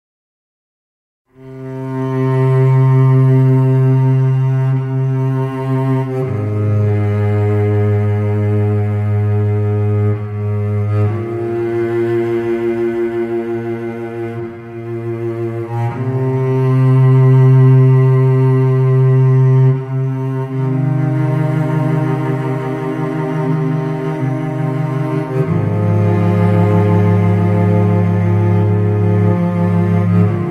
Heavenly Violin & Cello Instrumentals